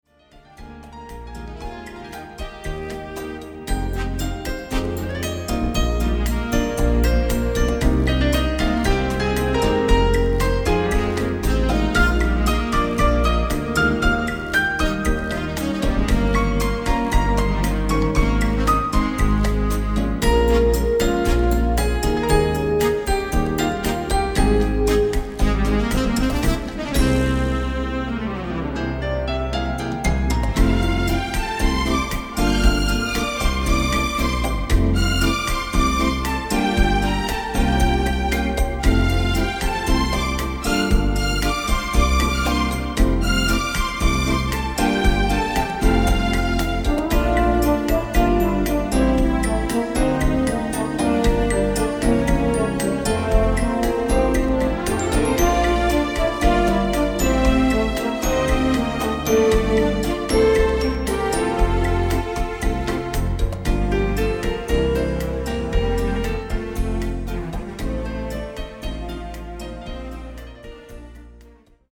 Bossa Nova